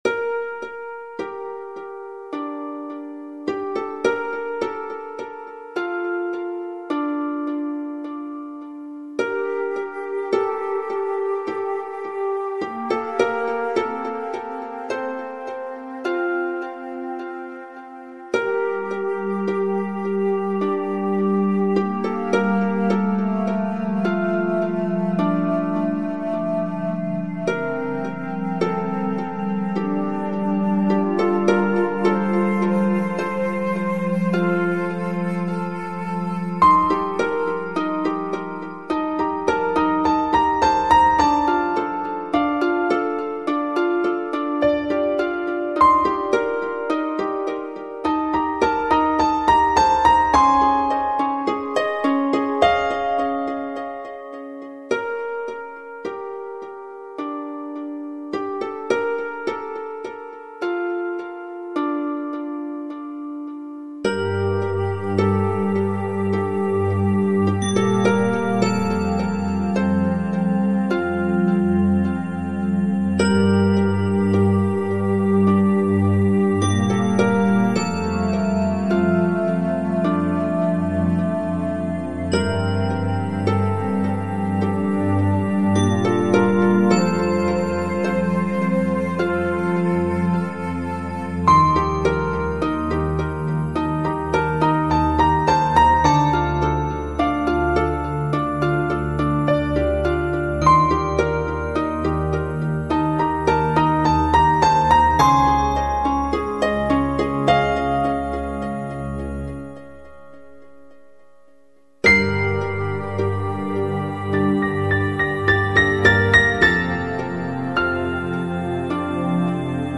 Жанр: Electronic, Ambient